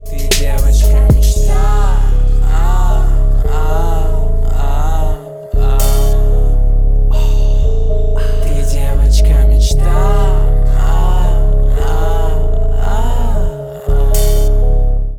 мужской голос
русский рэп
спокойные